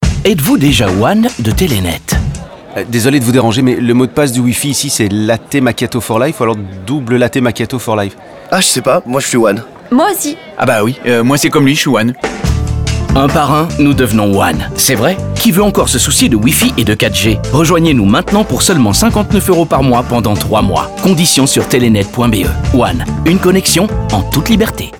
telenet-one-radio-q3-fr25-bar-a-caffe-gmix.mp3